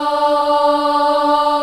Index of /90_sSampleCDs/USB Soundscan vol.28 - Choir Acoustic & Synth [AKAI] 1CD/Partition A/01-CHILD AHF
D3 CHIL AH-L.wav